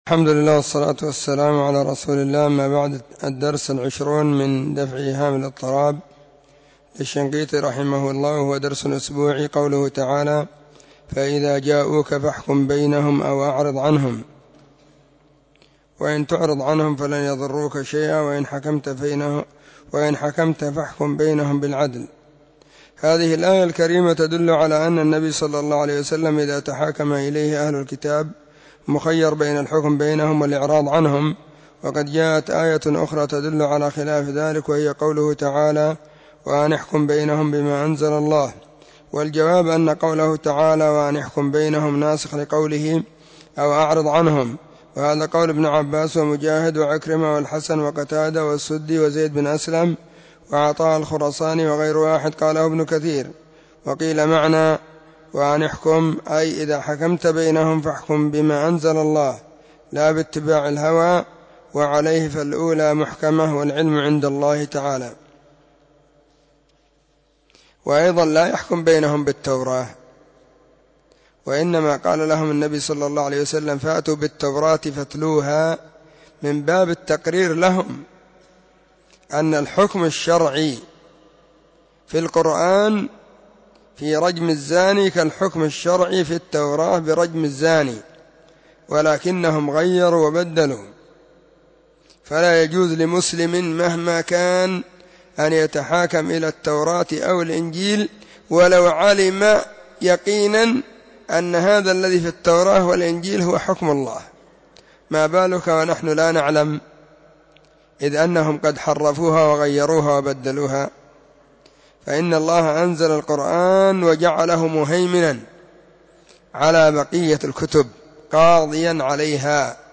⏱ [بعد صلاة الظهر في كل يوم الخميس]